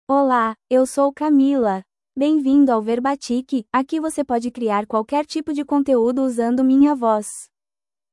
FemalePortuguese (Brazil)
CamilaFemale Portuguese AI voice
Camila is a female AI voice for Portuguese (Brazil).
Voice sample
Camila delivers clear pronunciation with authentic Brazil Portuguese intonation, making your content sound professionally produced.